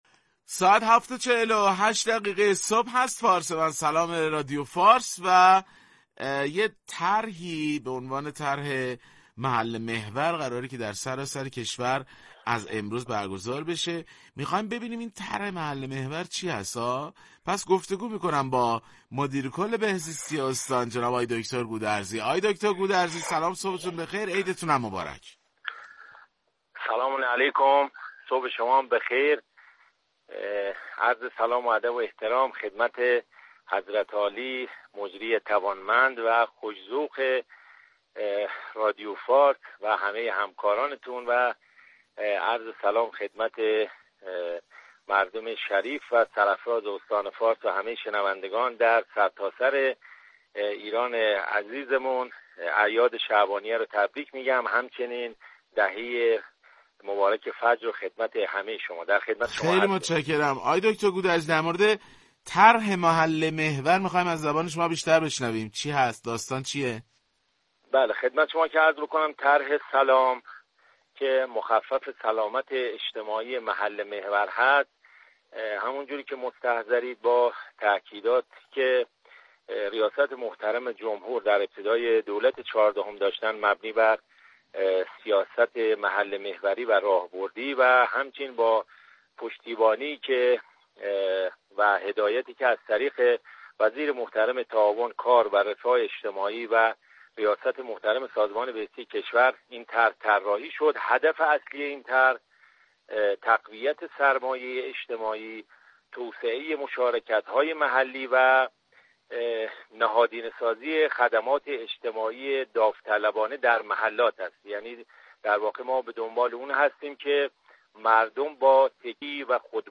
بشنوید | مصاحبه رادیو فارس با مدیرکل بهزیستی فارس